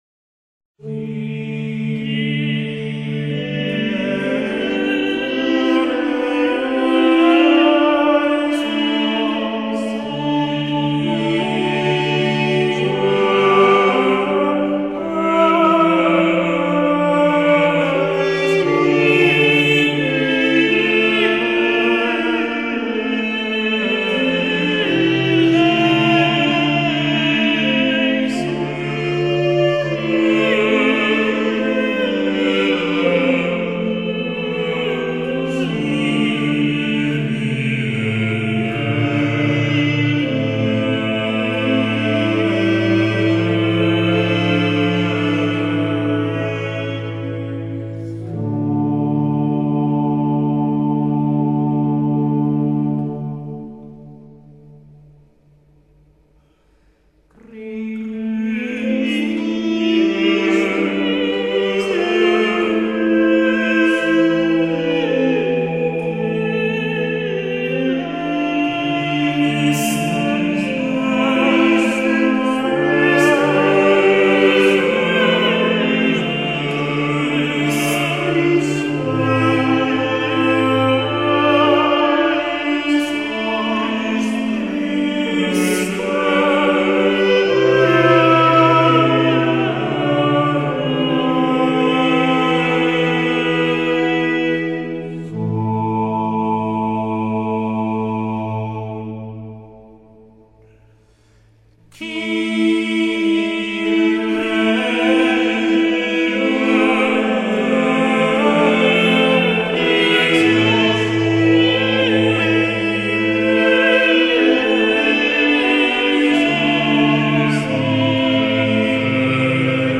Renesanso muzikoje dominavo daugiabalsė polifonija, pamažu gausėjo imitacinės polifonijos, atsirado aiškesnis ritmo ir metro skaičiavimas, buvo naudojamos senovinės dermės.
Palestrinos muzikos stilius pasižymėjo aiškumu, nuosekliu (beveik be šuolių) balsų judėjimu, retu chromatizmų vartojimu, konsonansiškumu.